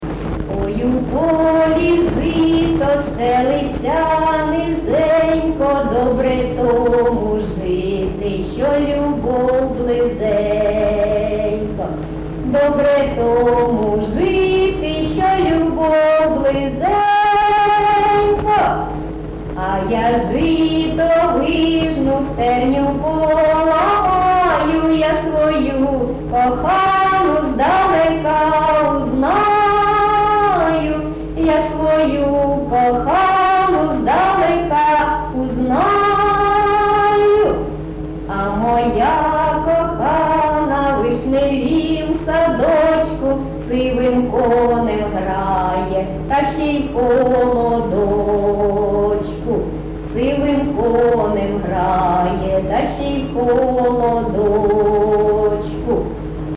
ЖанрПісні з особистого та родинного життя
Місце записус. Євсуг, Старобільський район, Луганська обл., Україна, Слобожанщина